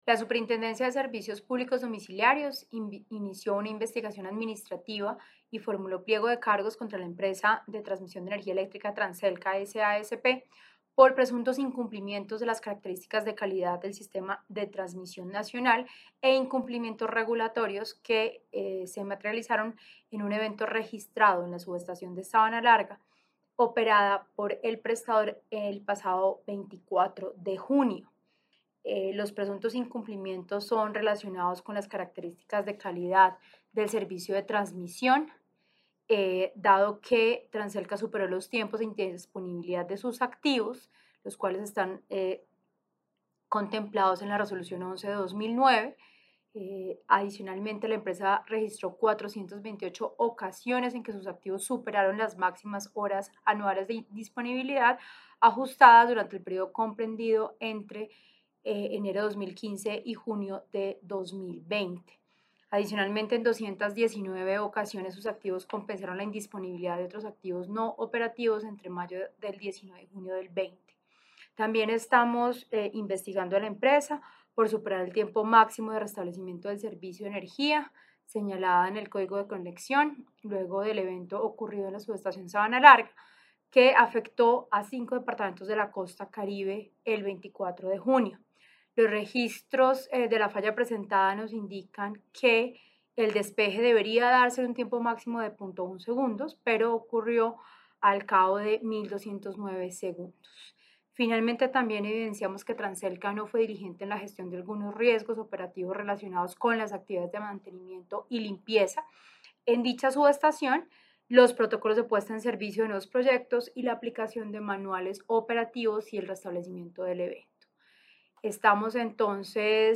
Declaraciones en video de la superintendente, Natasha Avendaño.